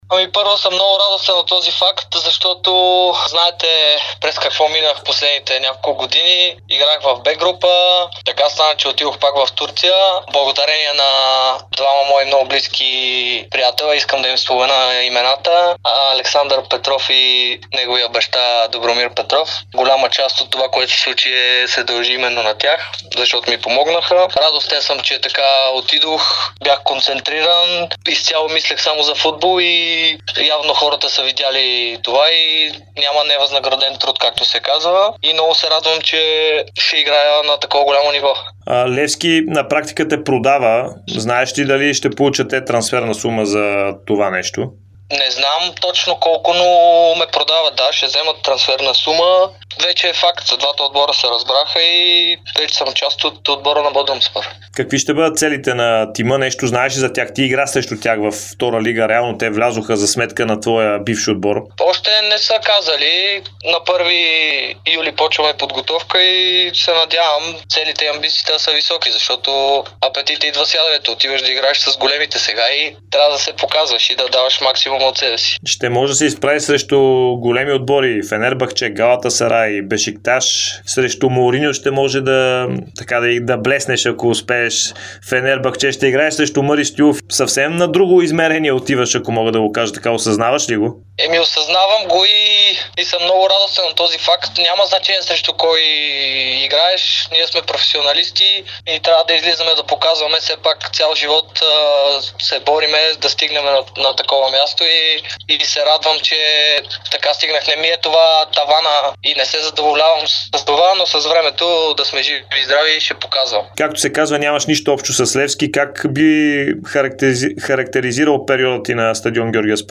Българският футболист Здравко Димитров даде ексклузивно интервю пред Дарик радио и dsport броени часове, след като стана факт трансферът му от Левски в турския елитен Бодрумспор. Крилото говори за престоя си при „сините“, целите в Турция, както и за желанието си да играе в националния отбор на България.